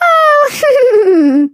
flea_kill_vo_06.ogg